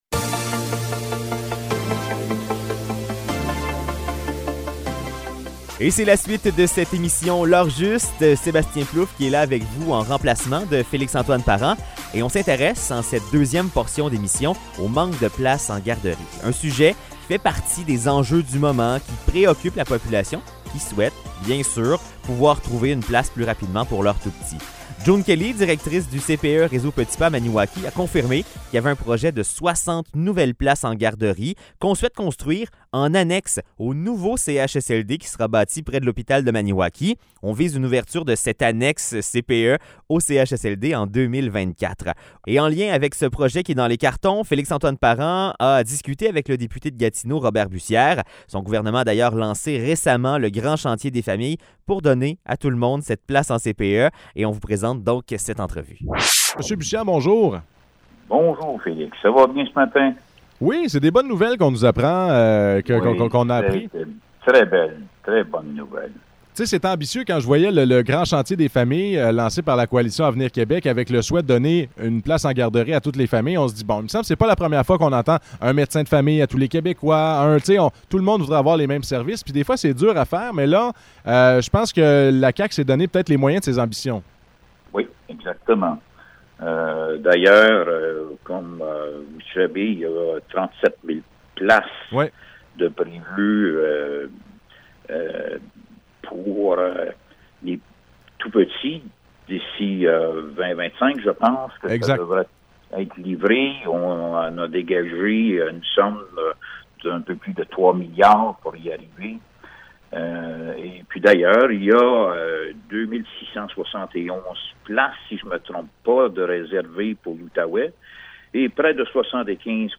Entrevue avec Robert Bussière, député de Gatineau, sur les nouvelles places en garderie